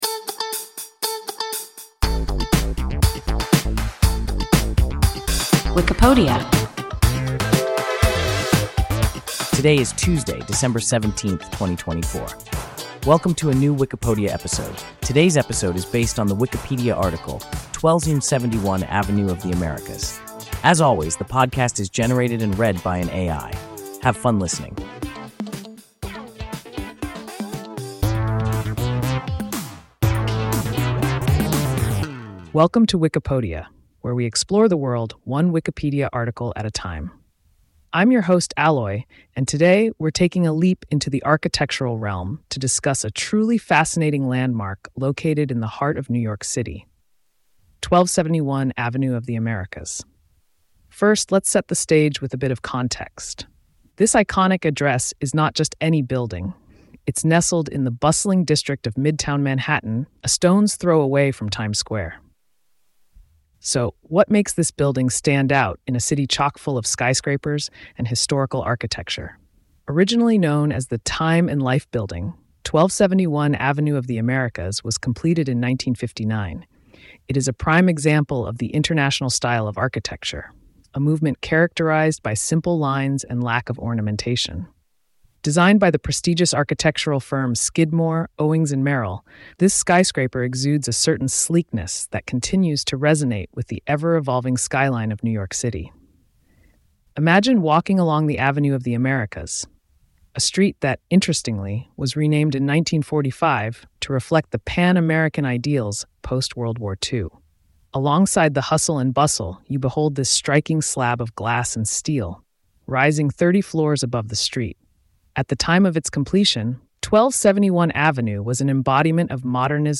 1271 Avenue of the Americas – WIKIPODIA – ein KI Podcast